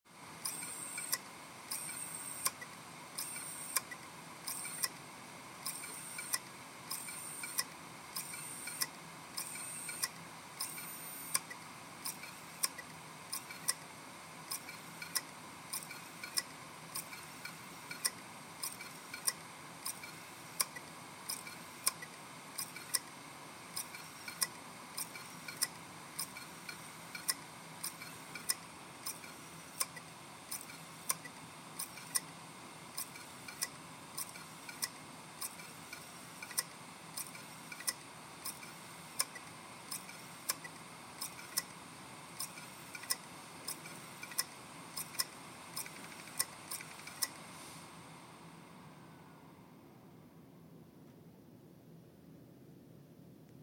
Der Lesearm war in der Mitte der Platte gehangen, habe ihn Vorsichtig in die Ausgangsposition geschoben. Die Laute welche meine Platte macht klingen nun leicht anders, als Laie würde ich sagen sie versucht den Anfang der Daten zu finden, packt es aber nicht...